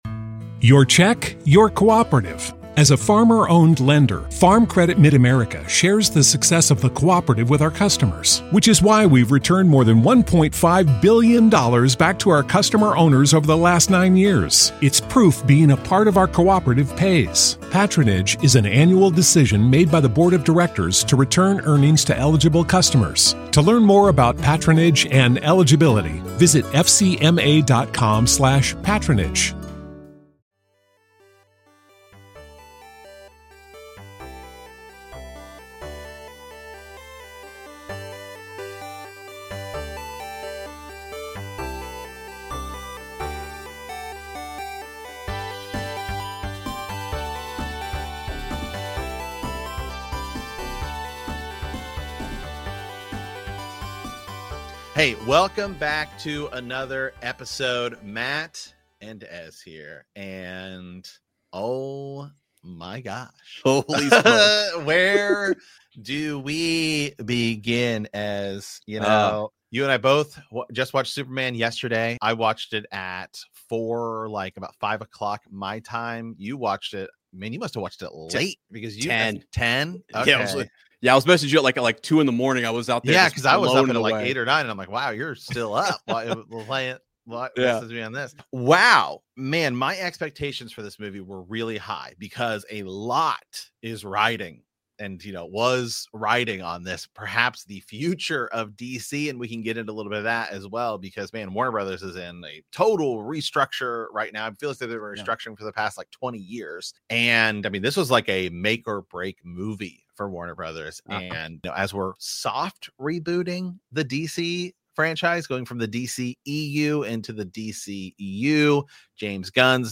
| Sunday Preview | SPEAKEASY 1:36:03 Play Pause 1d ago 1:36:03 Play Pause Play later Play later Lists Like Liked 1:36:03 Speakeasy is sophisticated sports talk — live Sunday through Thursday 8–10PM ET.